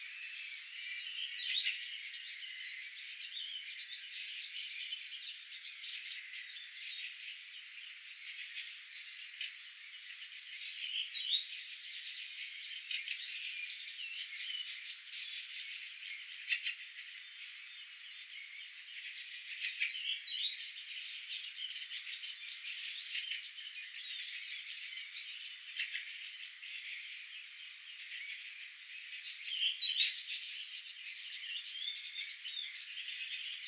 sinitiaisen laulua
Se laulaa joka aamu siinä kohdalla?
sinitiaisen_laulua_hiukan.mp3